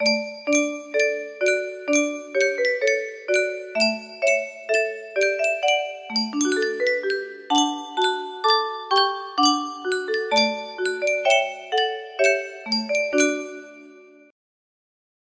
Yunsheng Music Box - Onward Christian Soldiers Y262 music box melody
Full range 60